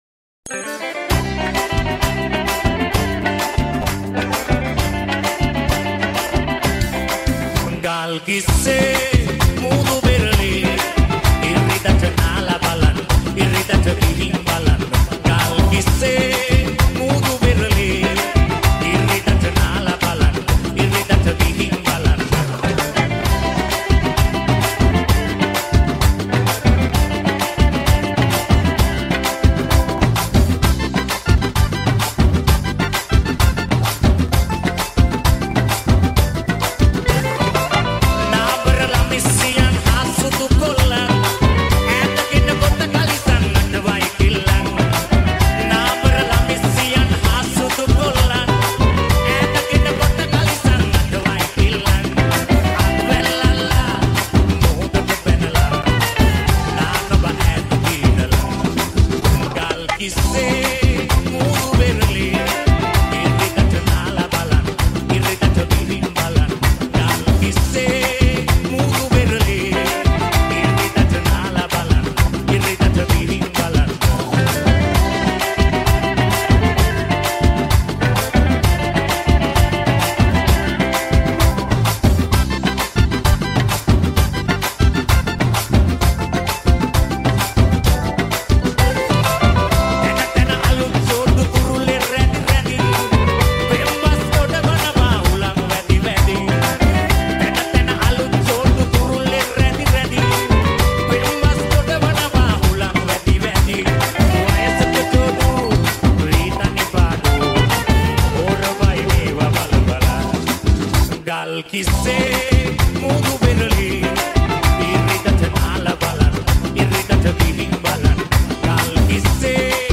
SL REMIX New Song